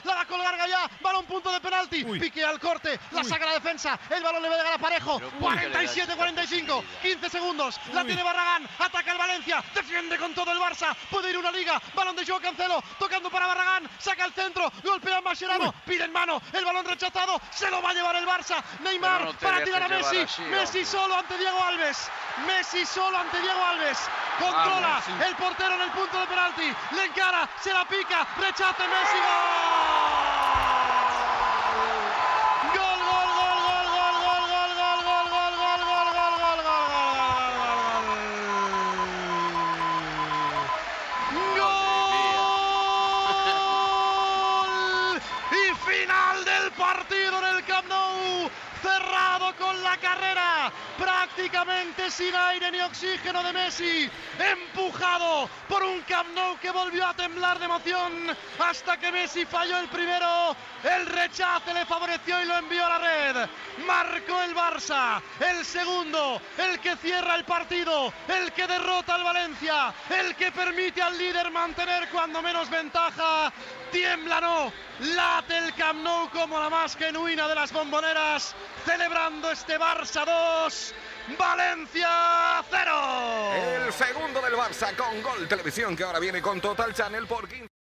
Transmissió del partit de la lliga masculina de futbol entre el Futbol Club Barcelona i el València Club de Futbol.
Narració del gol de Leo Messi i final del partit .